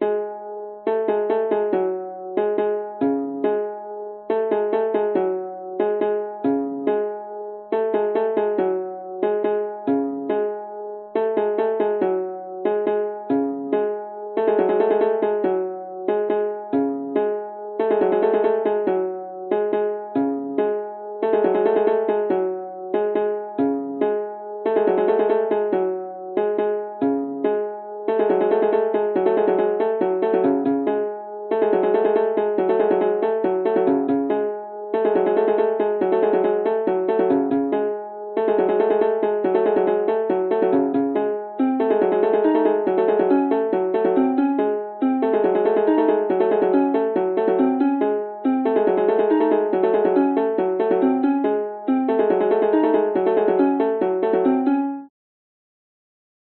我在这里做的安排听起来像是来自日本、中国、韩国或亚洲的东西。